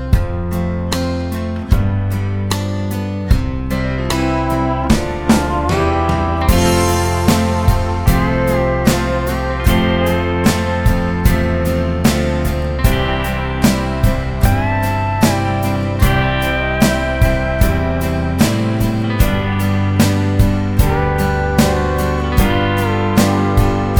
no Backing Vocals Country (Male) 3:20 Buy £1.50